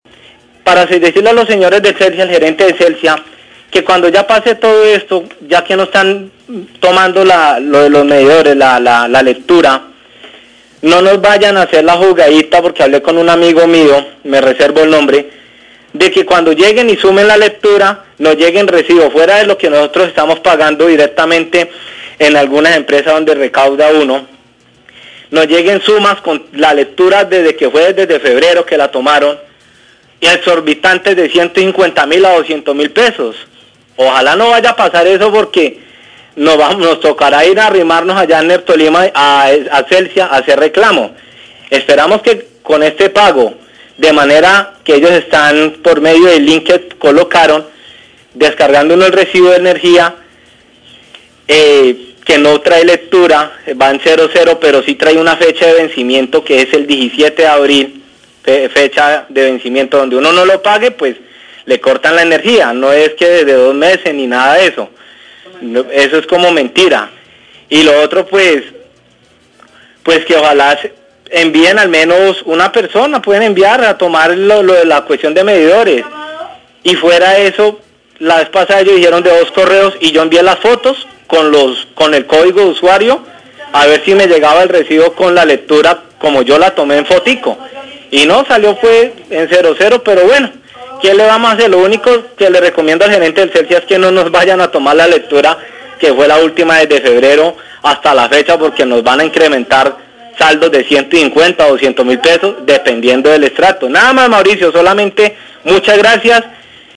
Oyente dice estar preocupado por costos de la factura una vez pase la cuarentena
Radio